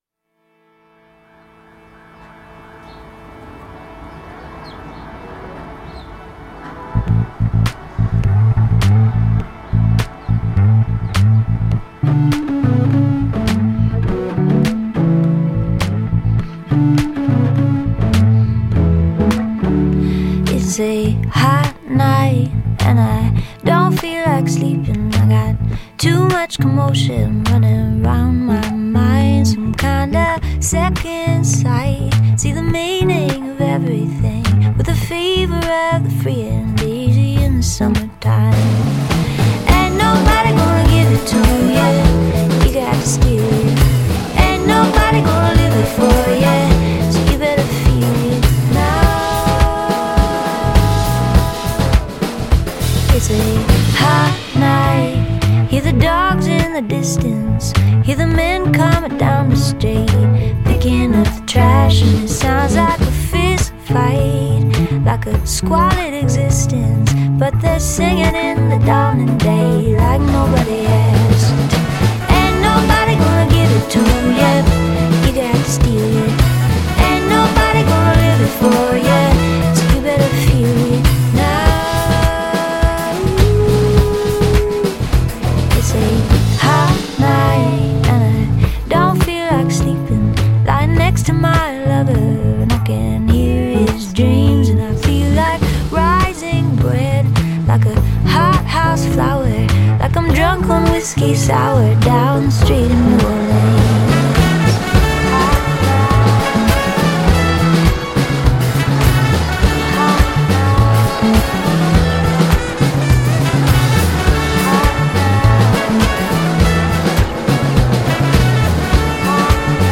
singer-songwriter
The light-hearted song
bass, keys, ukulele, backing vocals